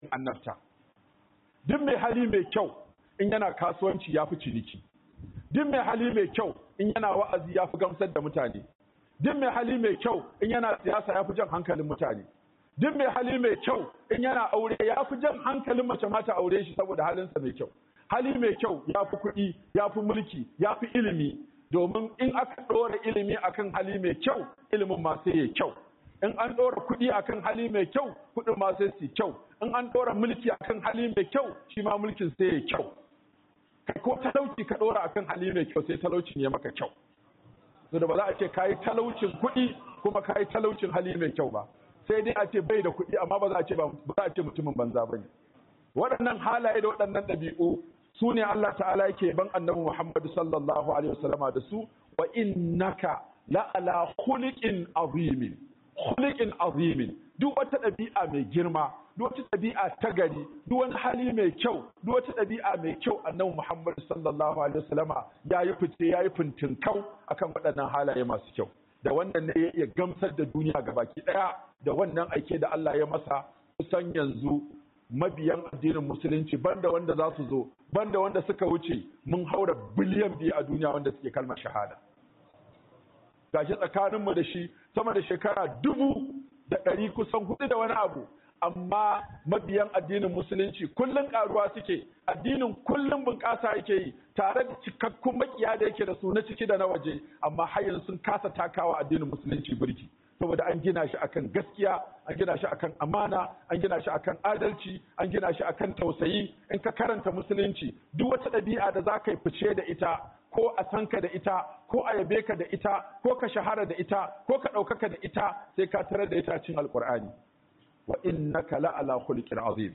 KYAWAWAN DABI'U SUKE SA KAYI RAYUWA MAI DADI 2025-11-21 - Huduba by Sheikh Aminu Ibrahim Daurawa